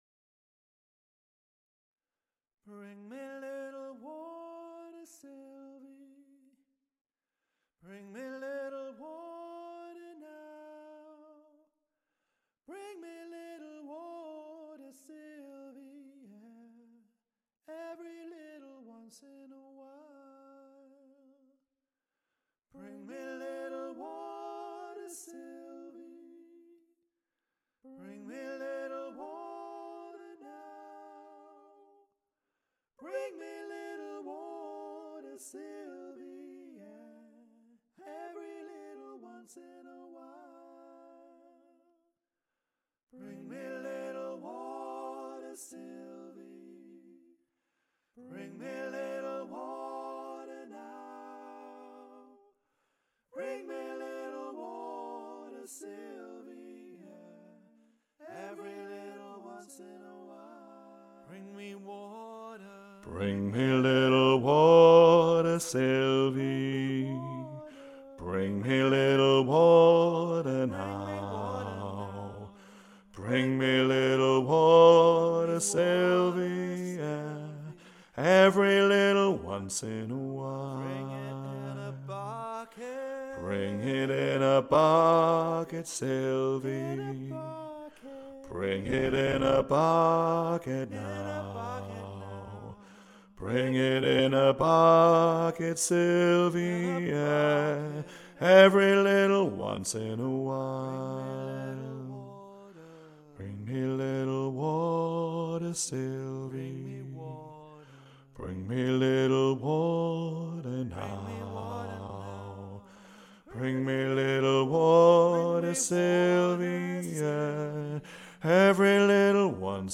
BRING-ME-LITTLE-WATER-SYLVIE-BASS.mp3